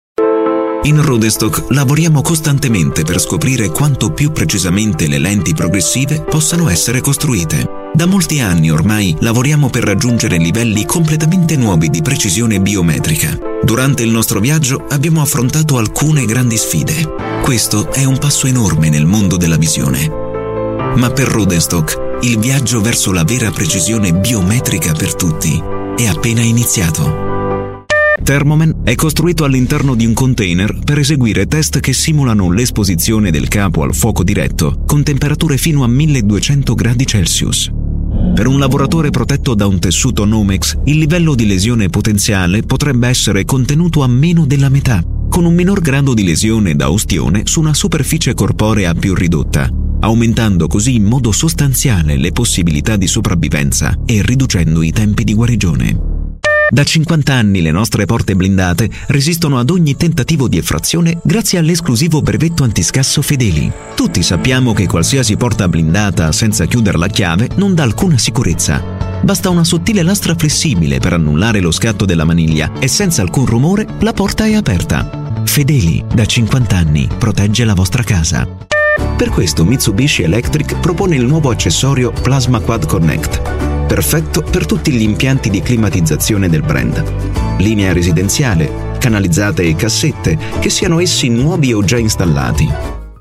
Voice Over
İtalyanca seslendirme sanatçısı. Erkek ses, ticari projeler ve kurumsal anlatımlar için profesyonel performans.